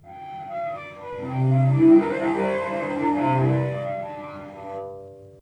Source: Mix of harmonic glisses C/G (12:00-14:00)
Processing: Granulated, with amplitude correlation with max=50:1, with 25;1 at start and end
Harmonic_Gliss_Mix.aiff